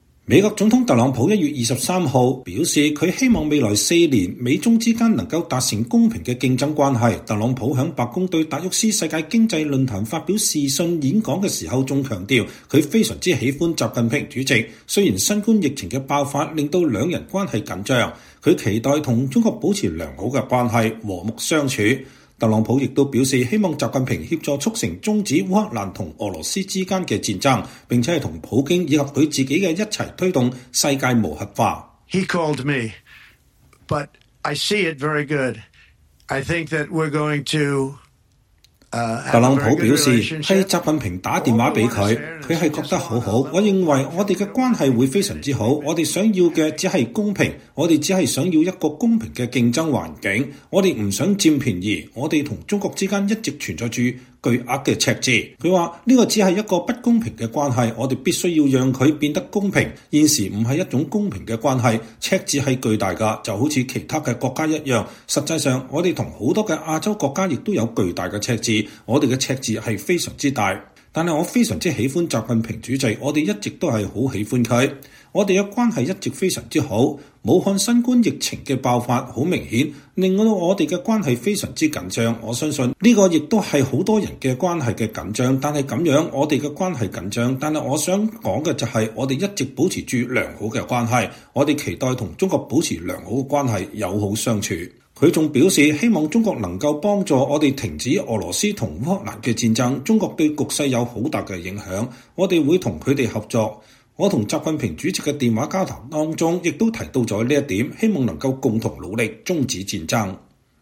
美國總統特朗普1月23日表示，他希望未來四年美中之間能達成公平的競爭關係。特朗普在白宮對達沃斯世界經濟論壇發表視訊演講時還強調他“非常喜歡習近平主席”，雖然新冠疫情的爆發令兩人關係緊張。